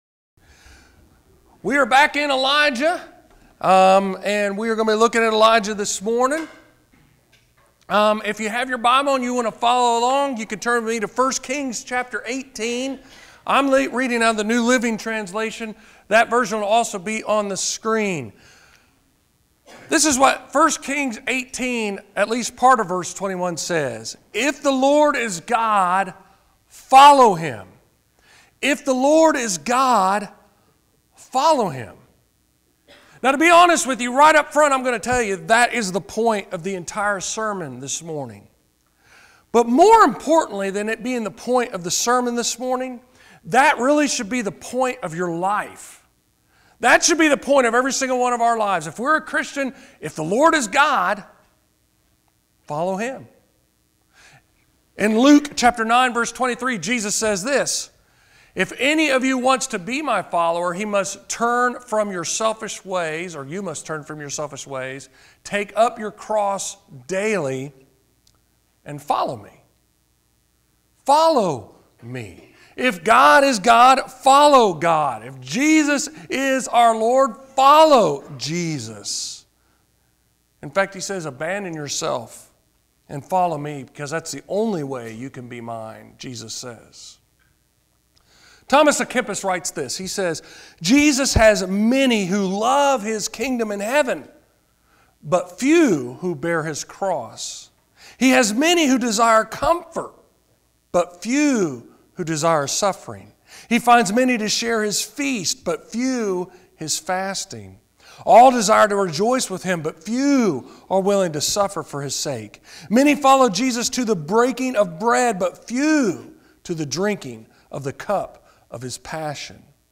Sermons in this series